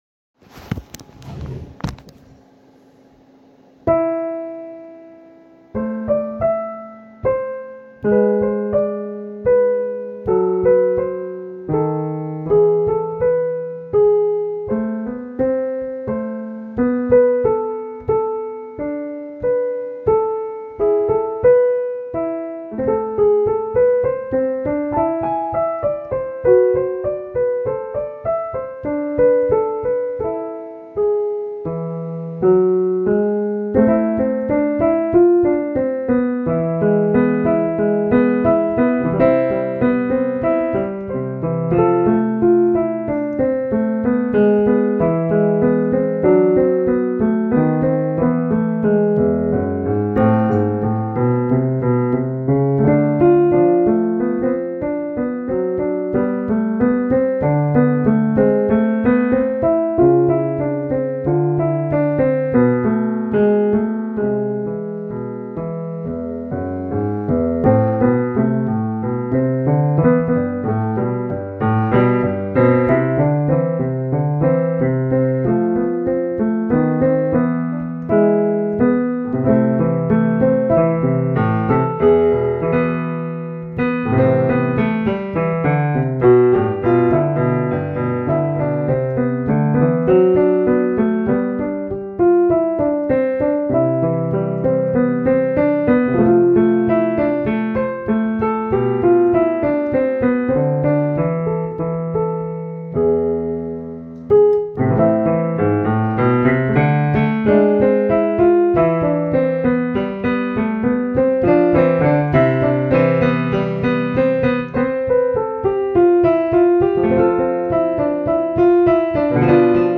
Random collection of stuff I've made:
Piano improvisation (theme 1) (1)